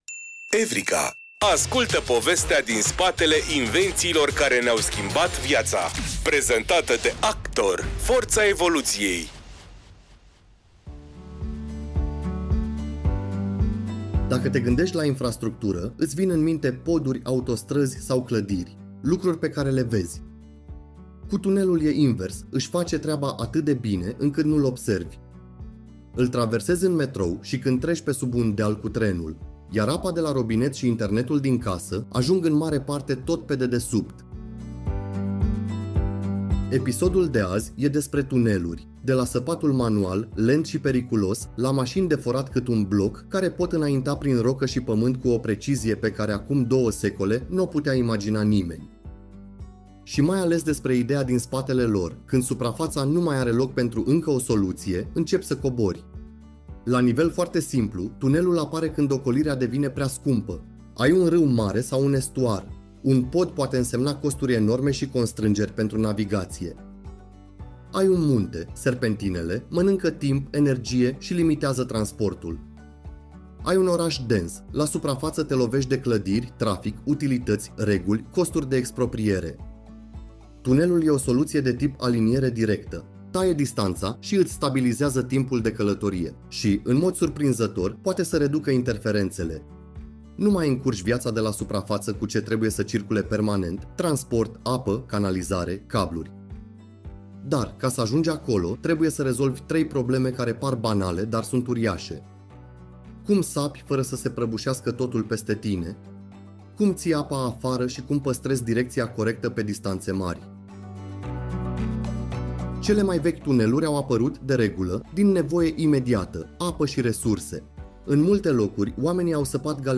Cu un ton cald și curios, „Evrika” transformă știința și tehnologia în aventuri umane, pe înțelesul tuturor. Producția este realizată cu ajutorul inteligenței artificiale, combinând cercetarea documentară cu narațiunea generată și editată creativ de echipa SOUNDIS România.